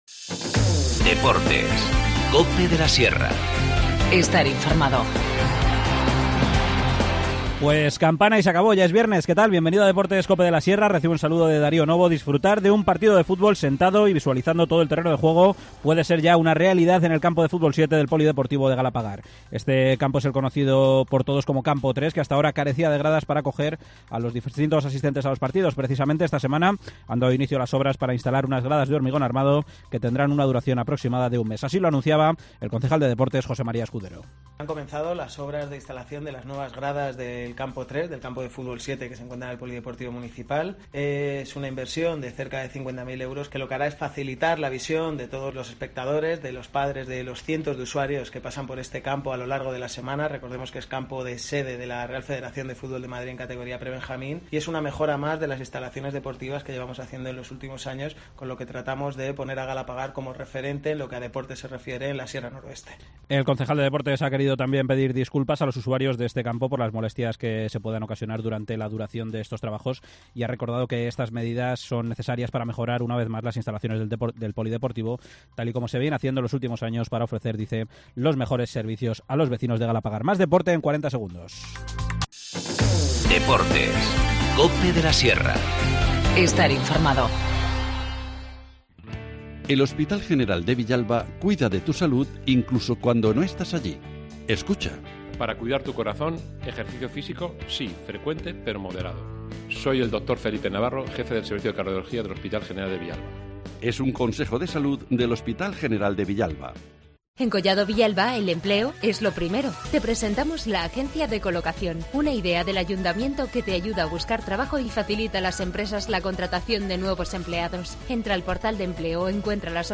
Galapagar comienza las obras para dotar de gradas al campo de fútbol 7. Nos da los detalles José María Escudero, concejal de Deportes.